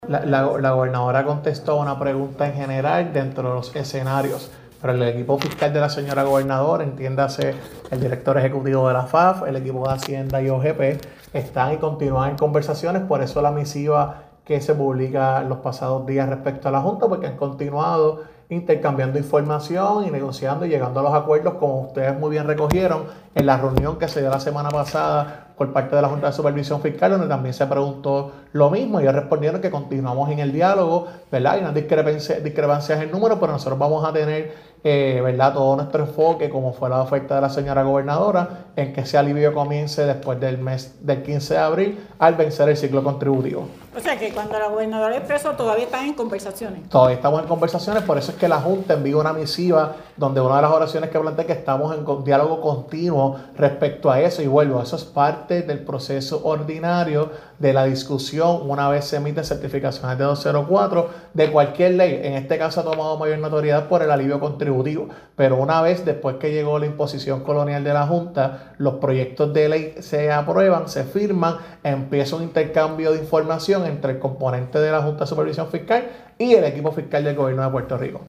(3 de marzo de 2026)-El Secretario de Asuntos Públicos del gobierno Jean Peña Payano aseguró que no es oficial una reducción en el propuesto incentivo contributivo e insistió que  continúan las conversaciones con la JSF para que se pueda materializar la promesa de la gobernadora Jennifer González a miles de contribuyentes.